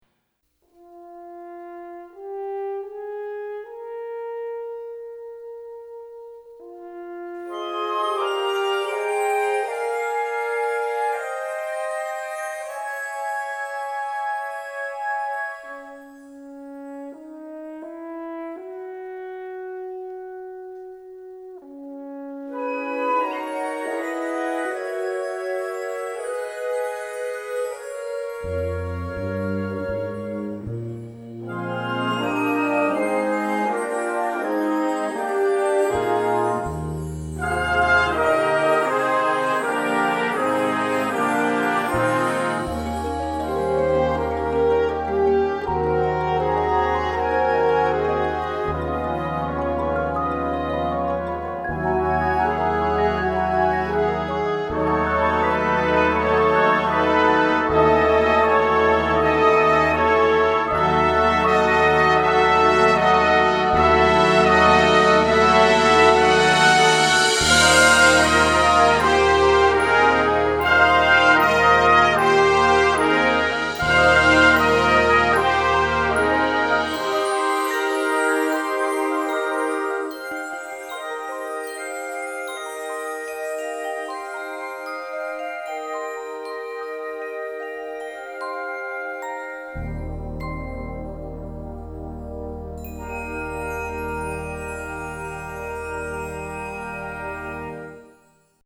Marching-Band
Besetzung: Blasorchester